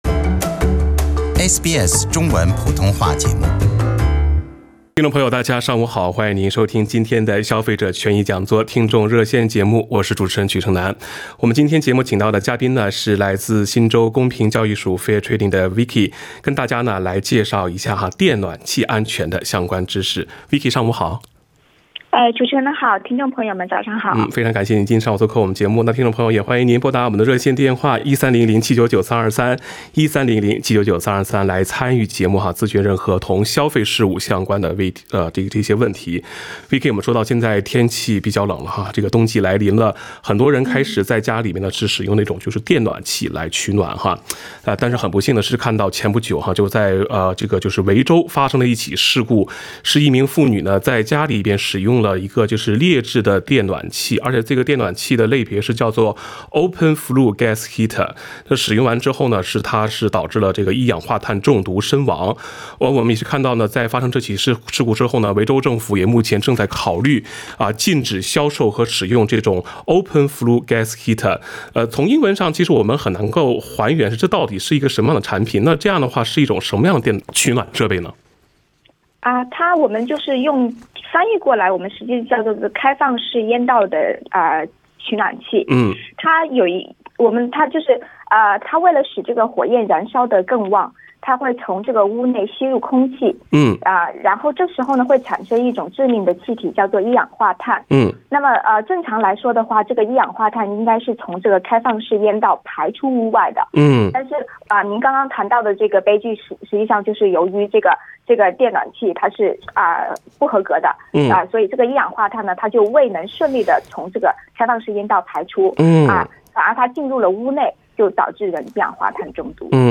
聽眾熱線